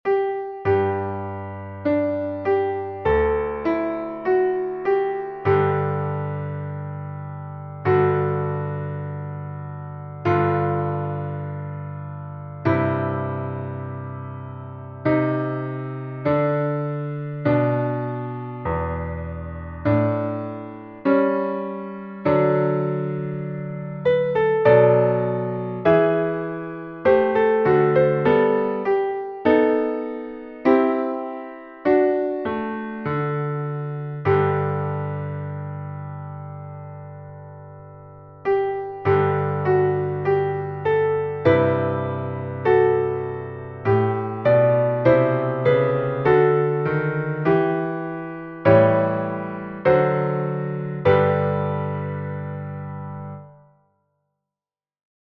Tonalità: Sol maggiore
Metro: 4/4
spartito e base musicale per violoncello